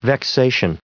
734_vexation.ogg